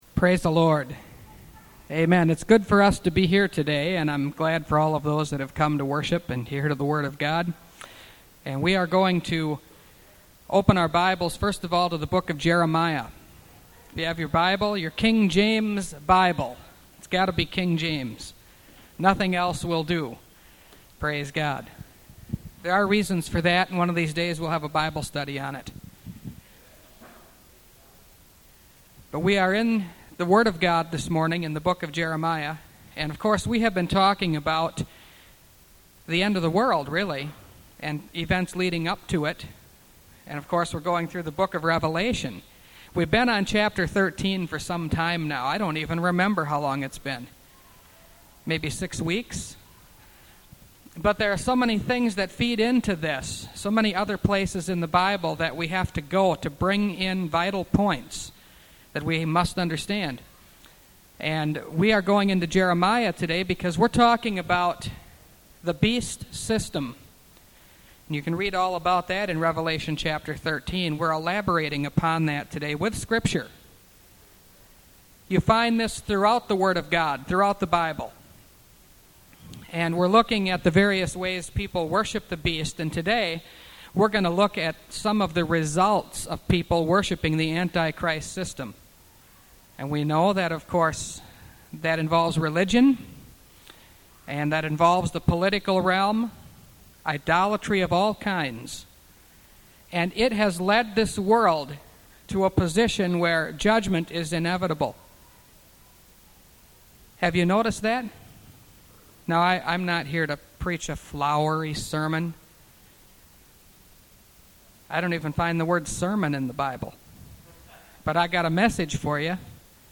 Revelation Series – Part 29 – Last Trumpet Ministries – Truth Tabernacle – Sermon Library